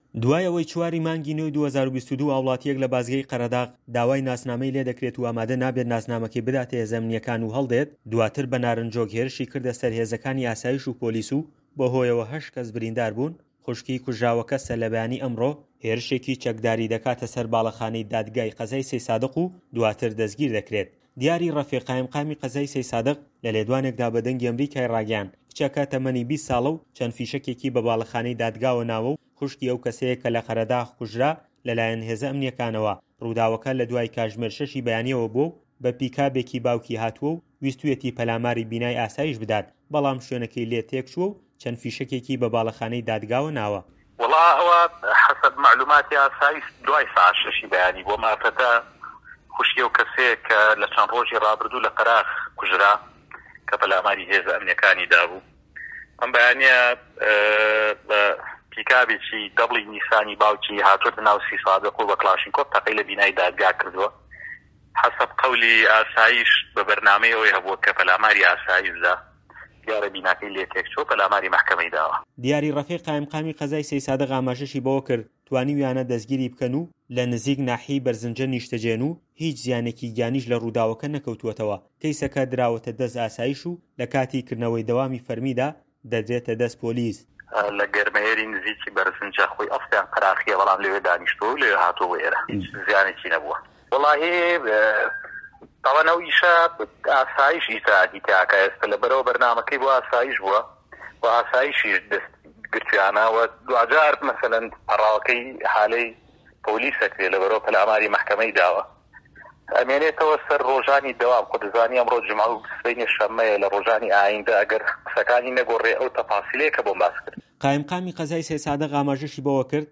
دیاری ڕەفیق قائیمقامی قەزای سەیدسادق لە لێدوانێکدا بە دەنگی ئەمەریکای ڕاگەیاند " کچەکە تەمەنی 20 ساڵە و چەند فیشەکێکی بە باڵەخانەی دادگاوە ناوە و خوشکی ئەو کەسەیە کە لە قەرەداغ لەلایەن هێزە ئەمنیەکانەوە کوژرا، ڕ‌ووداوەکە لە دوای کاتژمێر شەشی بەیانیەوە بووە و بە ئۆتۆمبێلێکی جۆری پیکاب کە هی باوکی بووە هاتووە ویستویەتی پەلاماری بینای ئاسایش بدات بەڵام شوێنەکەی لێ تێکچووە و چەند فیشەکێکی بە باڵەخانەی دادگاوە ناوە".